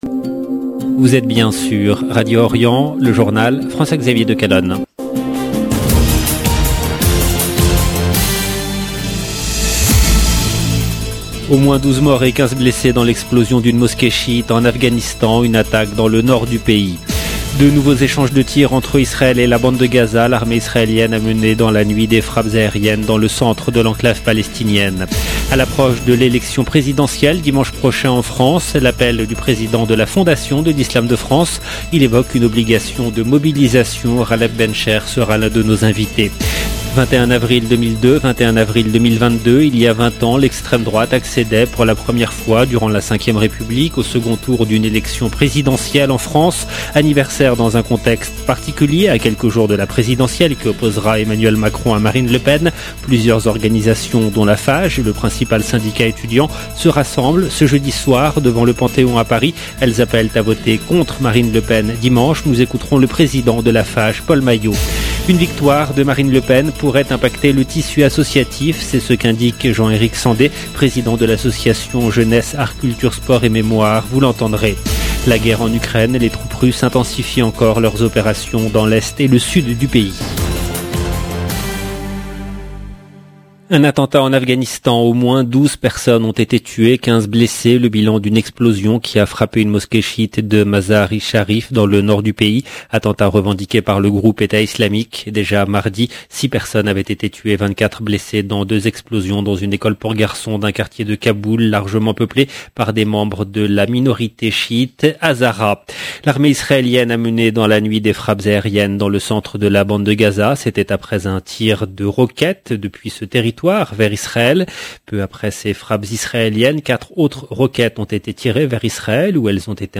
EDITION DU JOURNAL DU SOIR EN LANGUE FRANCAISE DU 21/4/2022